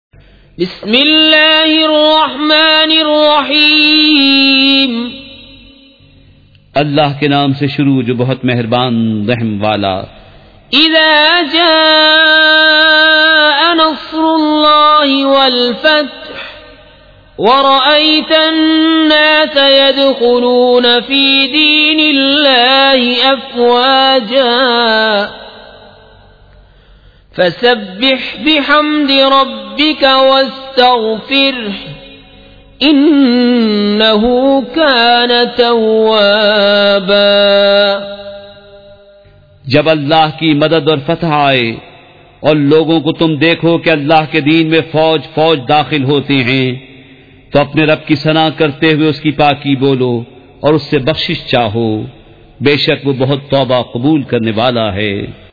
سورۃ النصر مع ترجمہ کنزالایمان ZiaeTaiba Audio میڈیا کی معلومات نام سورۃ النصر مع ترجمہ کنزالایمان موضوع تلاوت آواز دیگر زبان عربی کل نتائج 3754 قسم آڈیو ڈاؤن لوڈ MP 3 ڈاؤن لوڈ MP 4 متعلقہ تجویزوآراء